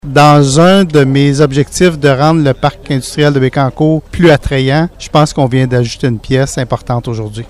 Le député de Nicolet-Bécancour y voit lui aussi une façon de développer le parc industriel et portuaire, qu’il considère comme le principal outil de développement au Québec.